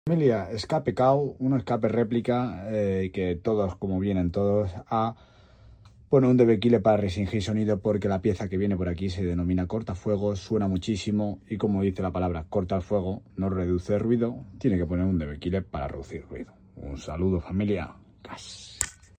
🥊 El escape réplica chino que suena como si estuvieras arrancando un avión de combate ✈🔥.
🥊 The Chinese replica exhaust that sounds like you’re starting up a fighter jet ✈🔥.